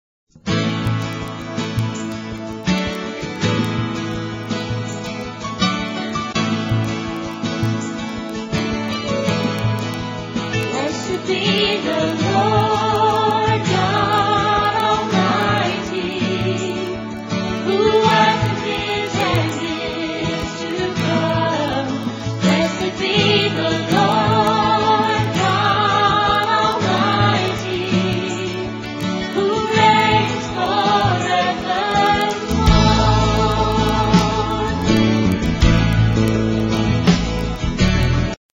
4 tracks WITH BACKGROUND VOCALS